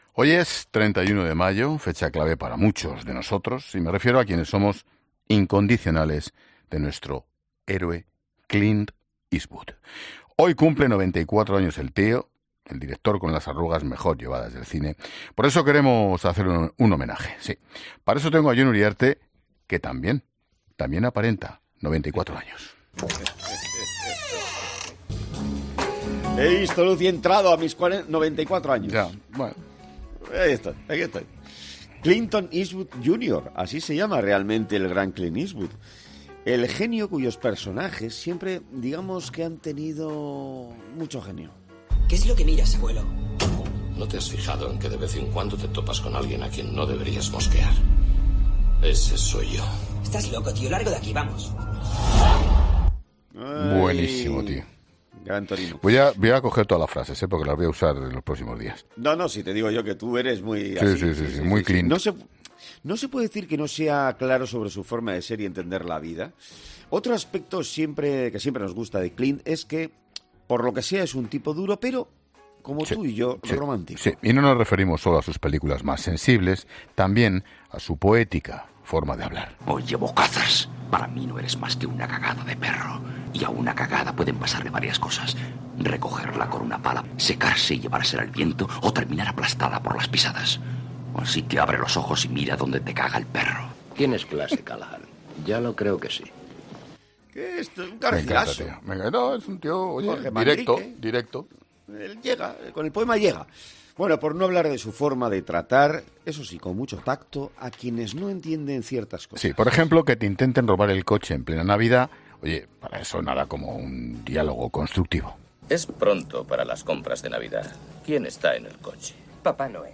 Y si no, escucha los cortes que han puesto en La Linterna recordando muchas de las películas que ha protagonizado Eastwood.